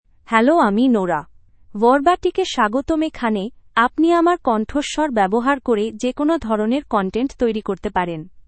FemaleBengali (India)
Nora — Female Bengali AI voice
Nora is a female AI voice for Bengali (India).
Voice sample
Listen to Nora's female Bengali voice.
Nora delivers clear pronunciation with authentic India Bengali intonation, making your content sound professionally produced.